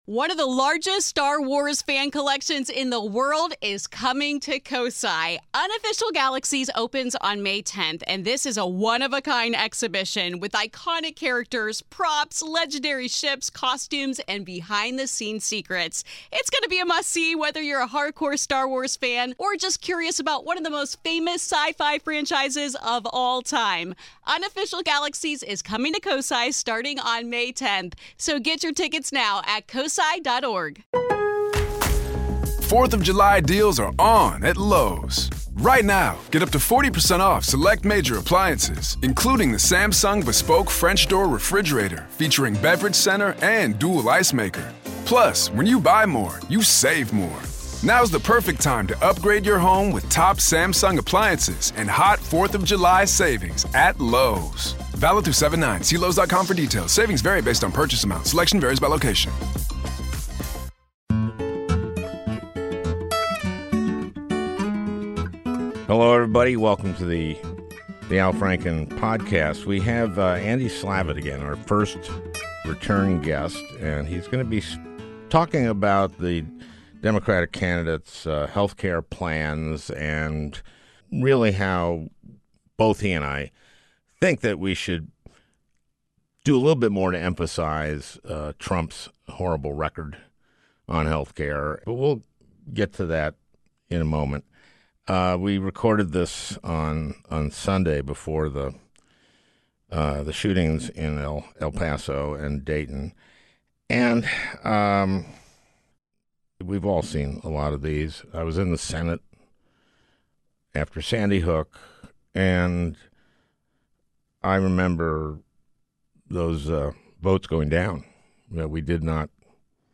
A Conversation with Andy Slavitt